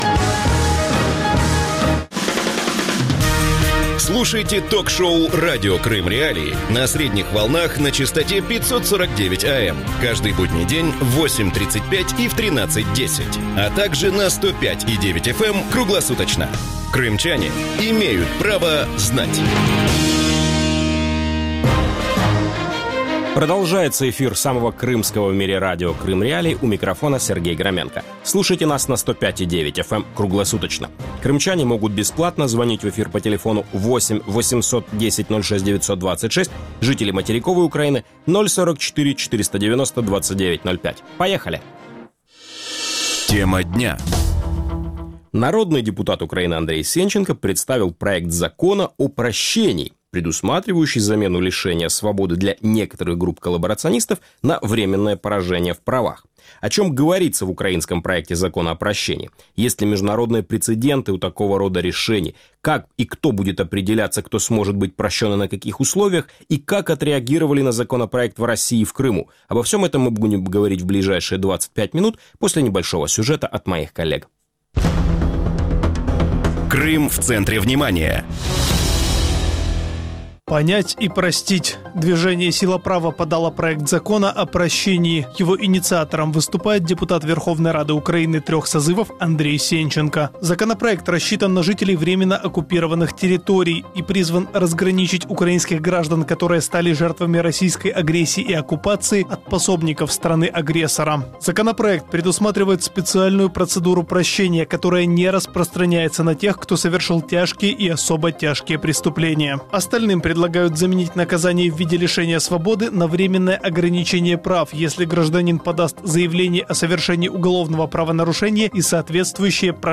О чем говорится в проекте закона «О прощении»? Как в Украине будут определять коллаборационистов после деоккупации Крыма? Кто из нарушителей украинского законодательства может быть прощен и на каких условиях? Гости эфира: Андрей Сенченко, бывший вице-премьер-министр Крыма